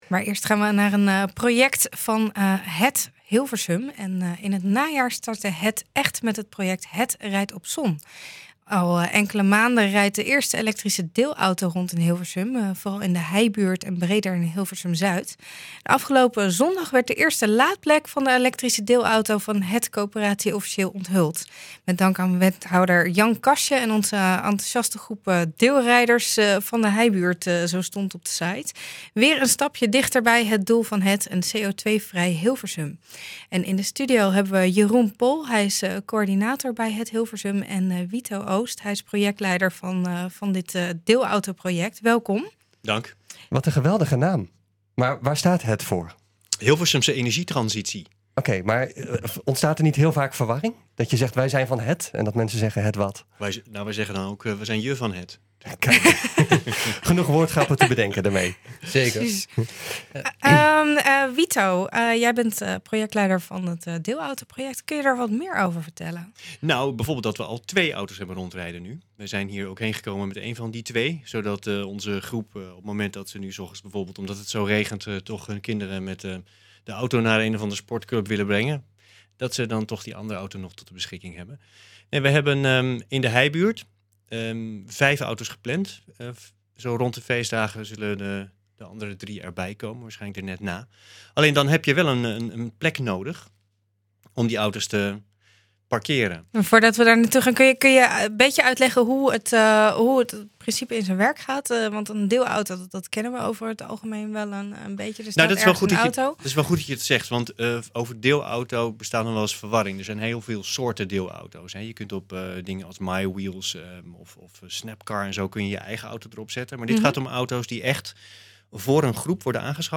In de studio